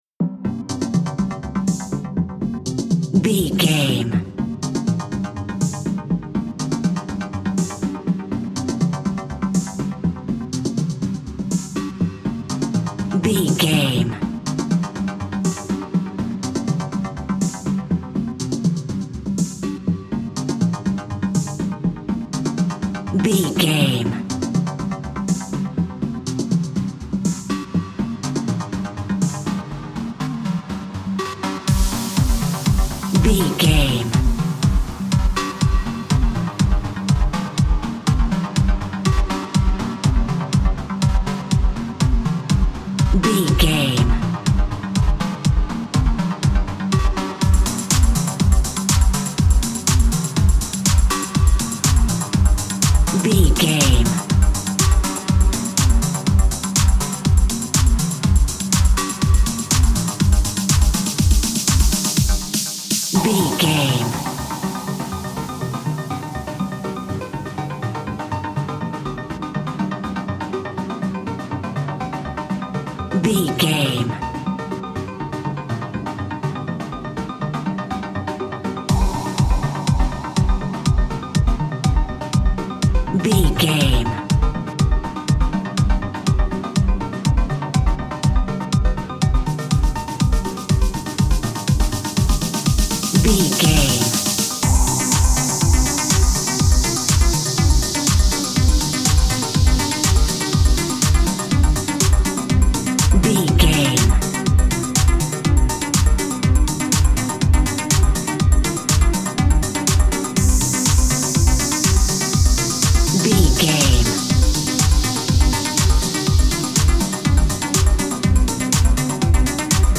Aeolian/Minor
groovy
uplifting
energetic
cheerful/happy
synthesiser
drum machine
house
electro dance
techno
synth bass
upbeat